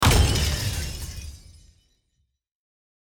Download Medium Explosion sound effect for free.
Medium Explosion